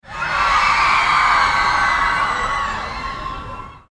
Screaming Crowd Sound Effect
Intense and emotional crowd reaction sound. Frantic yelling crowd audio clip. Human sounds.
Screaming-crowd-sound-effect.mp3